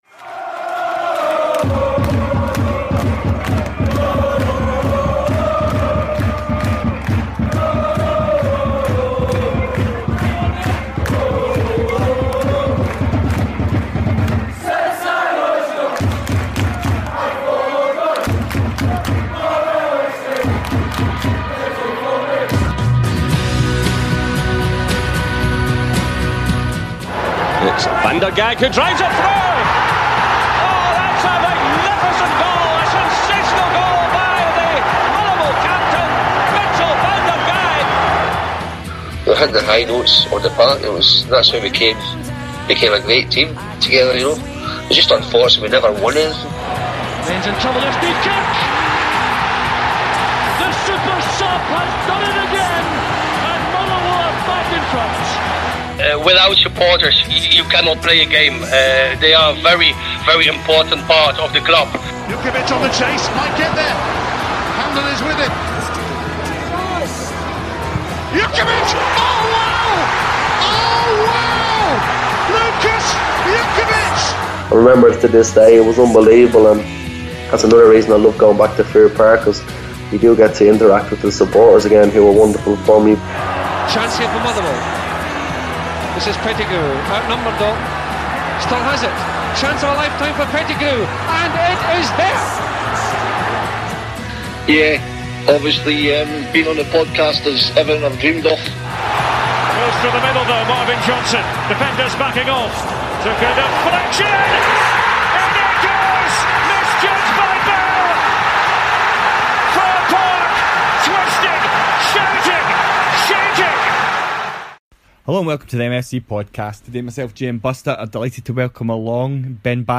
Recorded shortly after the full-time whistle in the 0-0 draw with Celtic